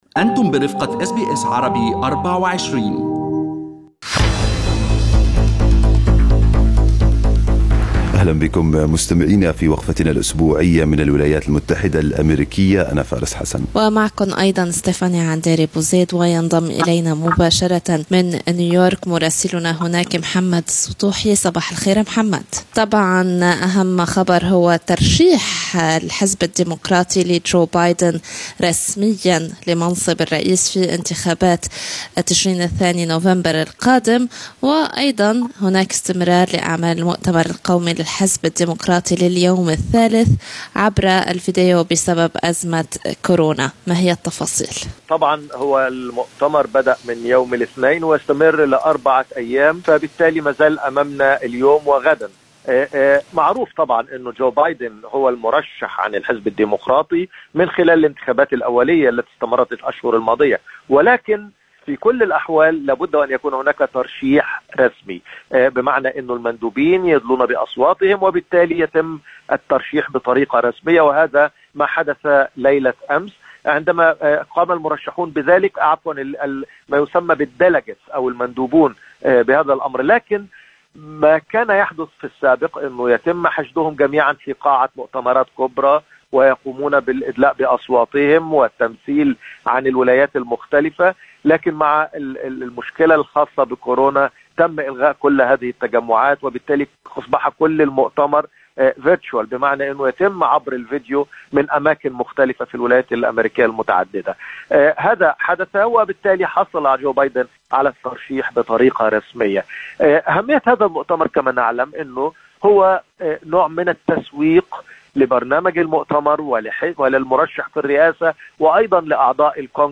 من مراسلينا: أخبار الولايات المتحدة الأمريكية في أسبوع 20/8/2020